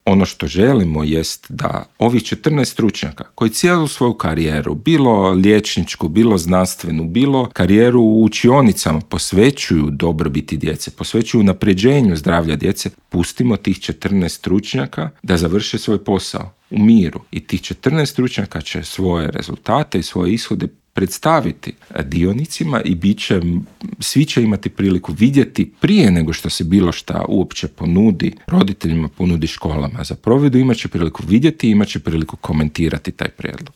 Više od 50 tisuća djece u Hrvatskoj suočava se s mentalnim teškoćama, broj djece s teškoćama u zagrebačkim osnovnim školama više se nego udvostručio u zadnjih deset godina, a više od trećine, odnosno čak 36 posto djece u Hrvatskoj je pretilo, dok je to na razini Europe slučaj s njih 25 posto, iznio je alarmantne podatke Hrvatskog zavoda za javno zdravstvo i UNICEF-a u Intervjuu Media servisa pročelnik Gradskog ureda za obrazovanje Luka Juroš i poručio: